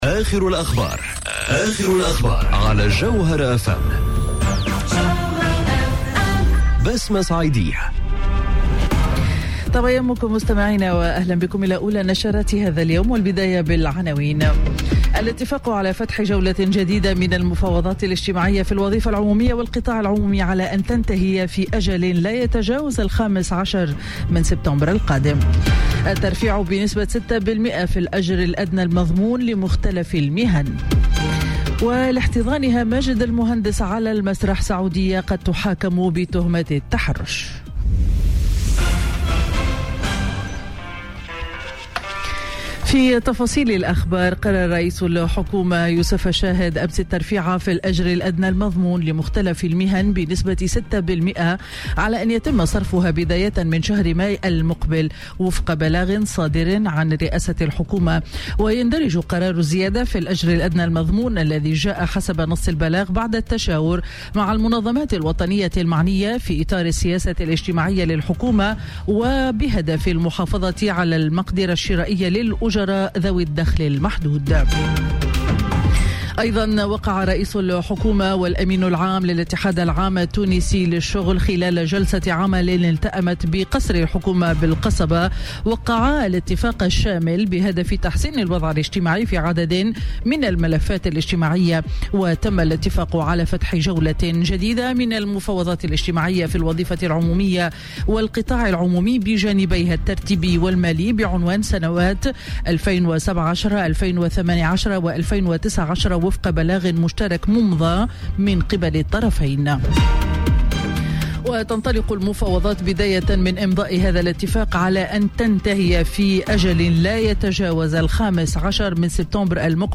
نشرة أخبار السابعة صباحا ليوم الأحد 15 جويلية 2018